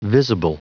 Prononciation du mot visible en anglais (fichier audio)
Prononciation du mot : visible